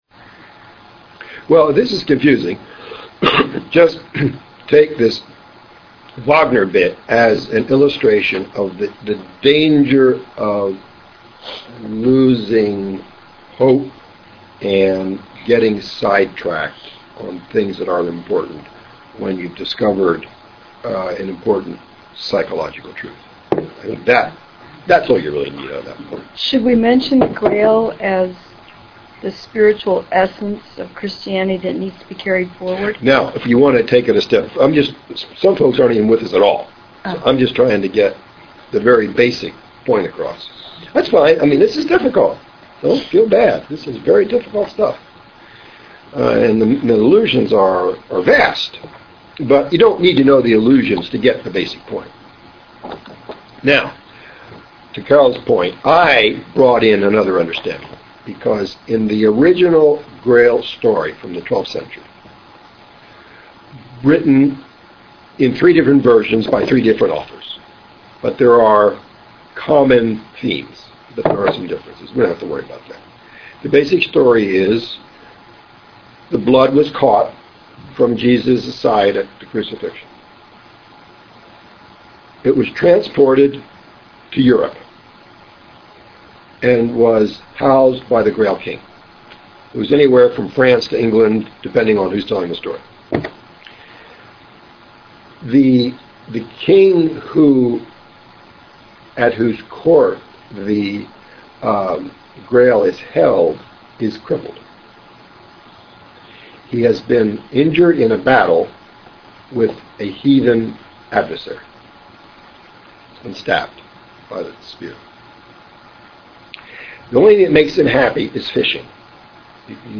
Seminar on C.G. Jung's Red Book